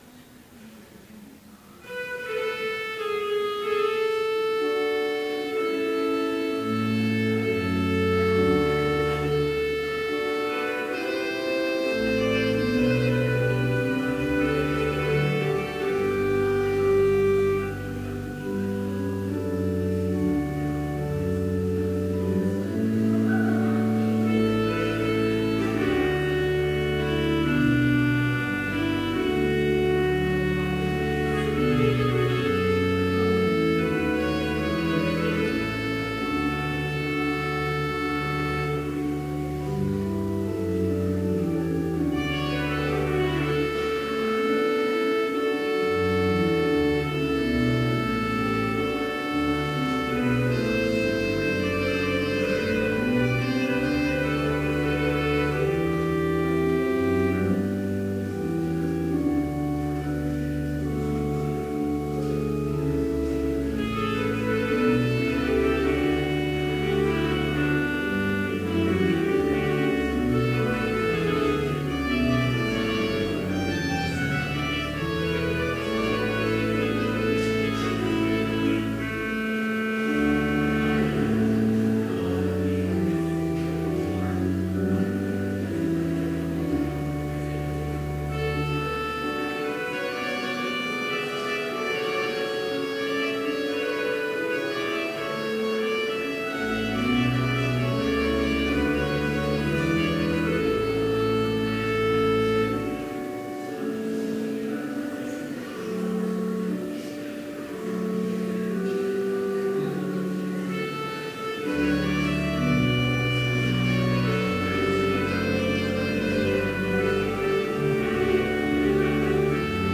Chapel worship service held on February 16, 2017, BLC Trinity Chapel, Mankato, Minnesota, (video and audio available)
Complete service audio for Chapel - February 16, 2017
Order of Service Prelude Hymn 227, vv. 1, 2, 5 & 6, Salvation Unto Us Is Come Reading: Philippians 3:7-11 GWN Devotion Prayer Hymn 227, vv. 10, 13 & 14, Faith to the cross… Blessing Postlude